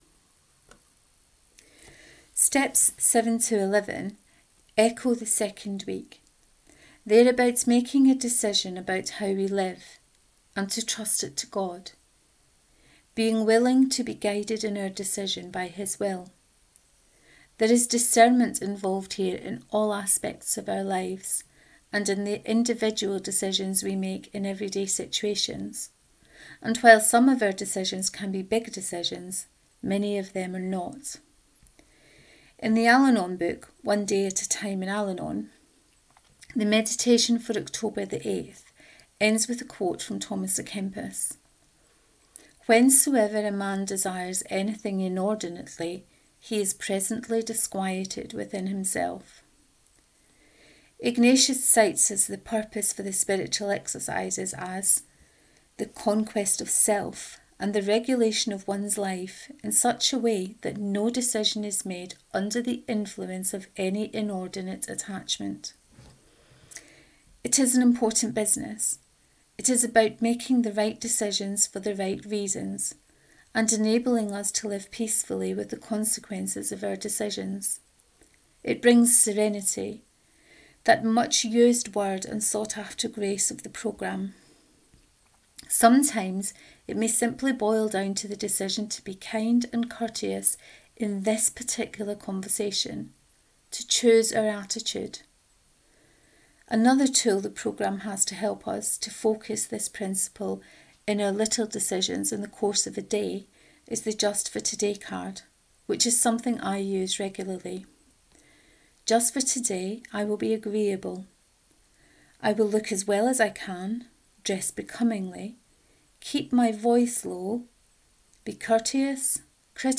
The Spiritual Exercises and The Twelve Steps 4, reading of this post.